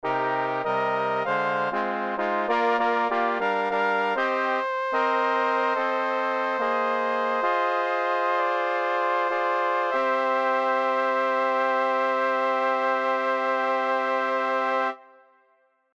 Key written in: C Minor
How many parts: 4
Type: Barbershop
All Parts mix: